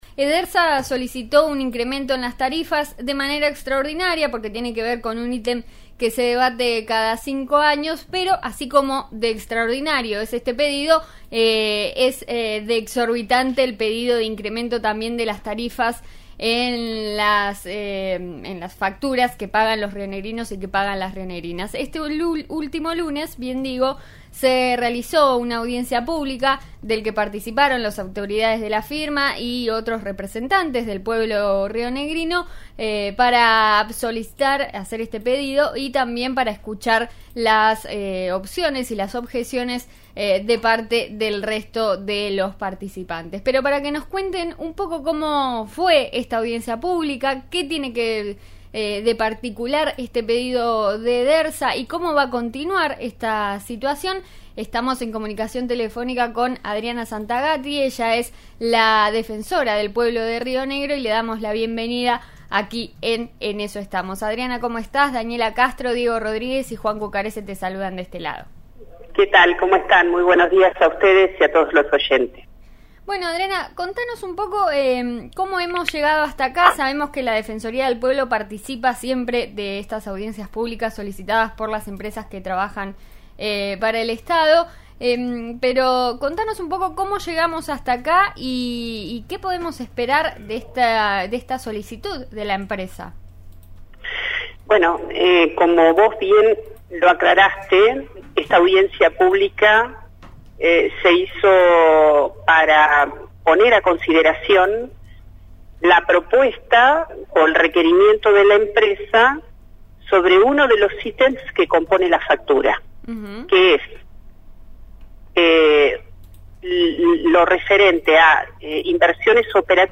'En eso estamos' de RN Radio entrevistó a Adriana Santagati, Defensora del Pueblo de Río Negro, sobre la solicitud de Edersa de aumentar su tarifa.